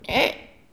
Les sons ont été découpés en morceaux exploitables. 2017-04-10 17:58:57 +02:00 124 KiB Raw Permalink History
bruit-animal_27.wav